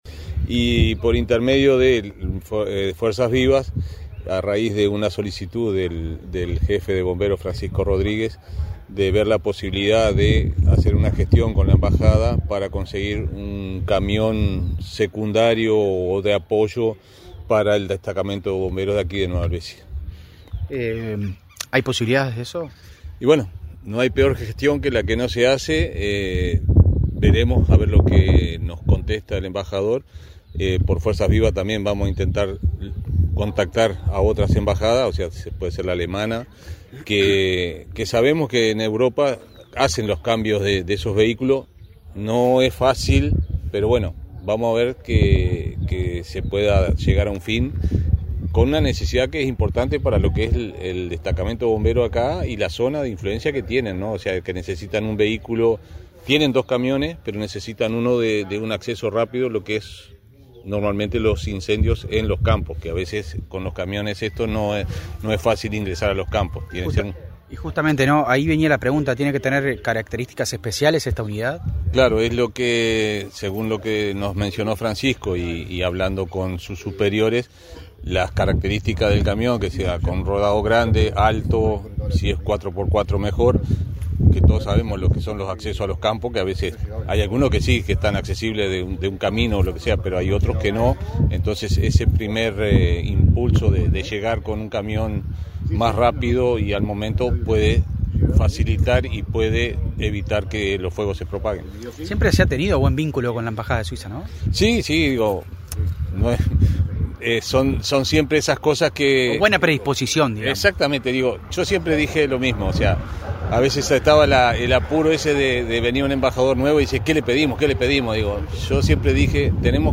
Compartimos las declaraciones